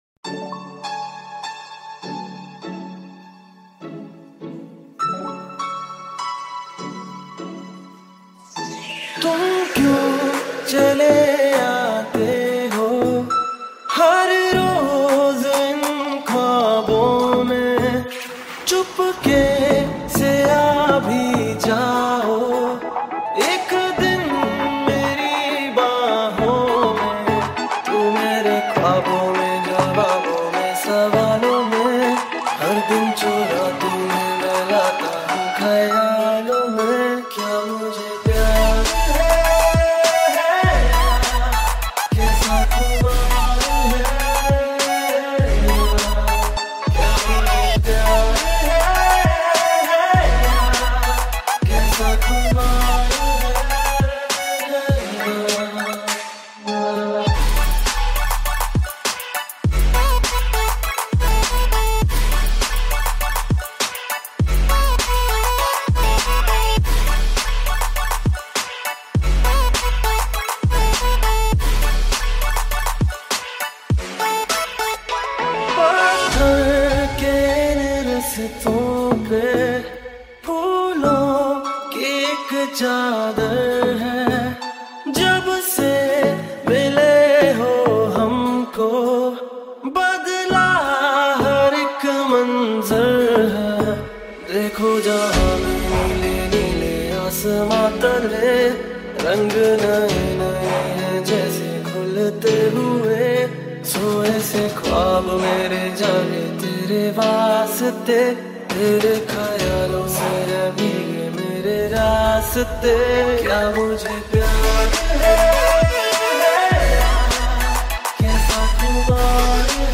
High quality Sri Lankan remix MP3 (3).